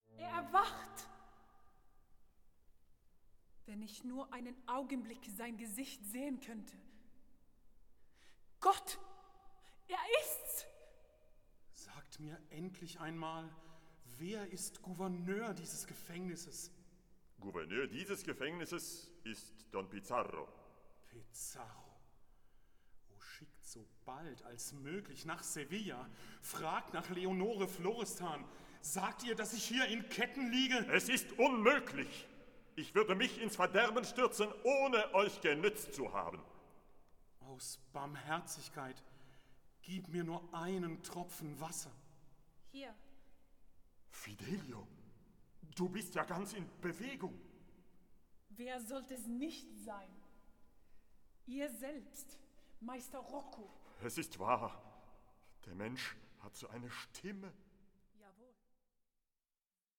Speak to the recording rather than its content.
in two studio sessions